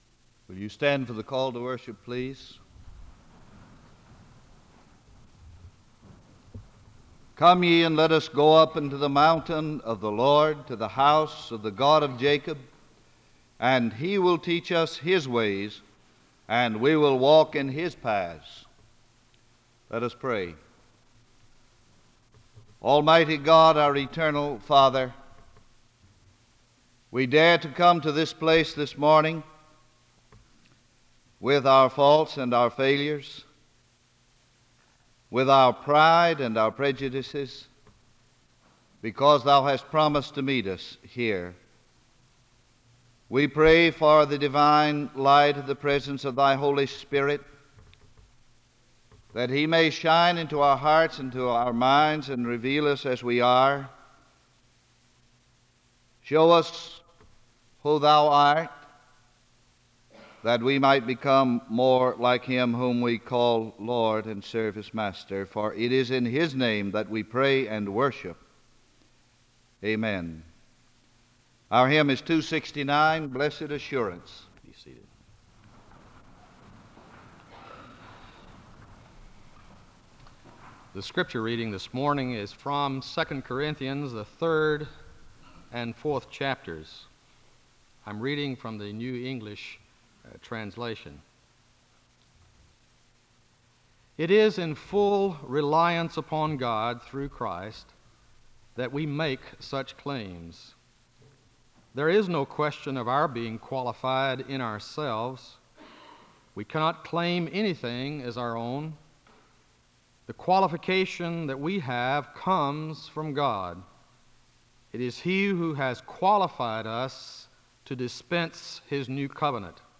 The service begins with a call to worship, and a word of prayer is given (00:00-01:15). The speaker reads from 2 Corinthians 3-4, and he gives a word of prayer (01:16-05:00). The choir sings a song of worship (05:01-08:24).
Location Wake Forest (N.C.)